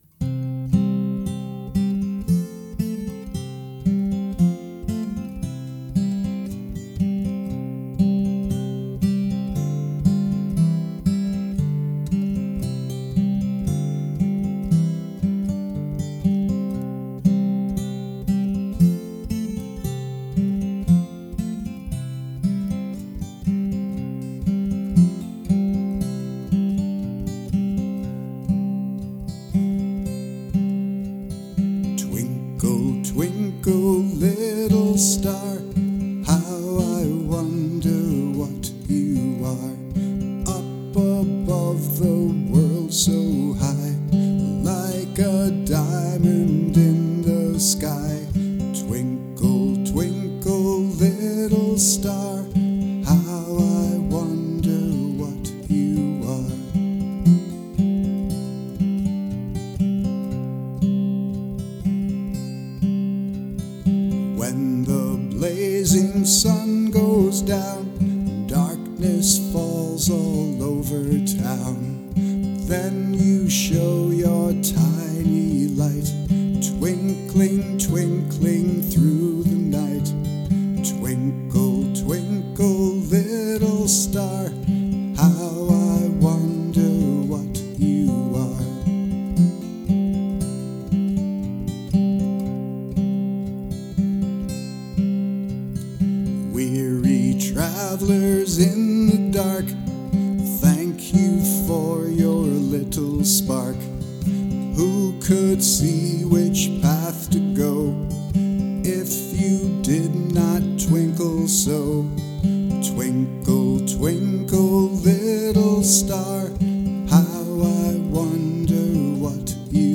It takes me back to being a kid…beautiful melody that we take for granted…I love your version of it.
Just a Fantastic historical synopsis of some of the history of this tune, love your guitar playing and singing.